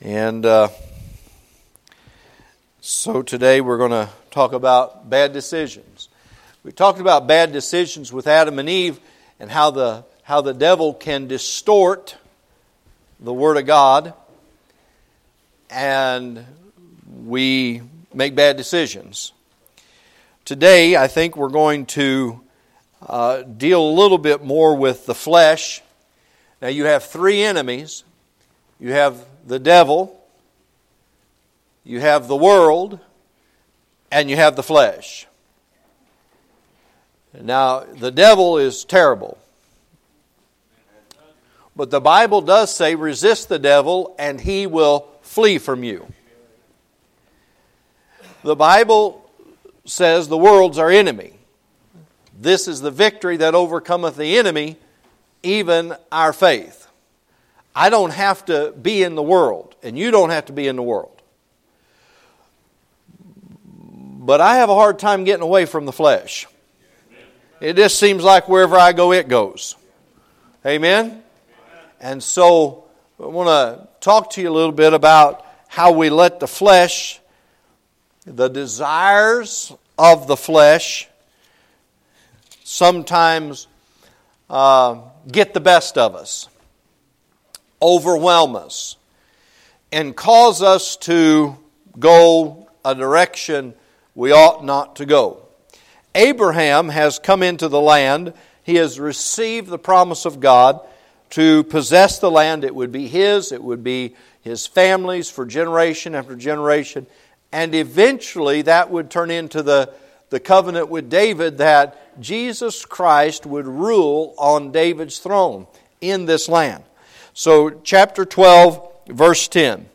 A message from the series "Miscellaneous."